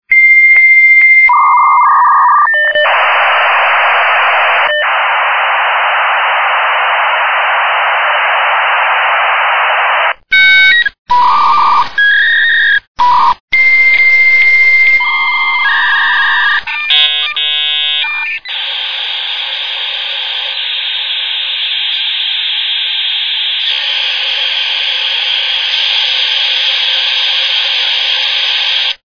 Рингтоны.
Сделано по мотивам вот этих сэмплов .
modemrington1.mp3